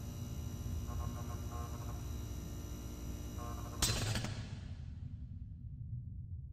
Ambience Track Sound Buttons